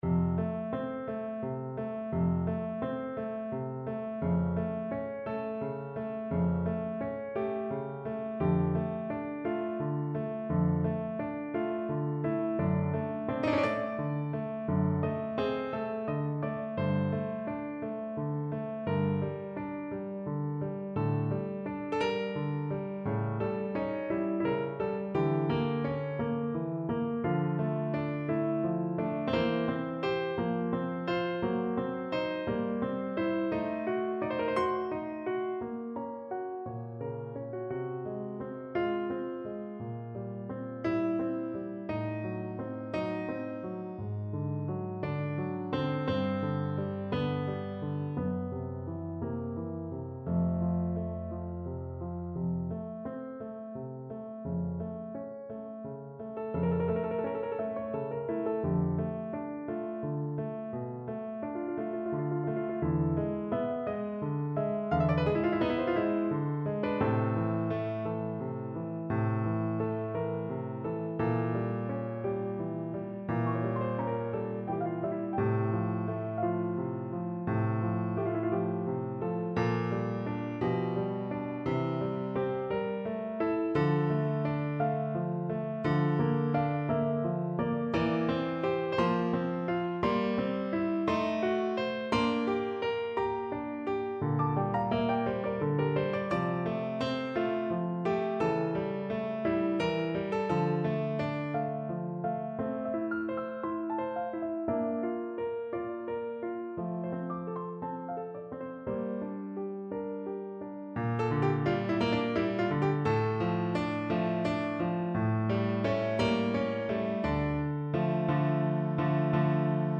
No parts available for this pieces as it is for solo piano.
6/8 (View more 6/8 Music)
Piano  (View more Advanced Piano Music)
Classical (View more Classical Piano Music)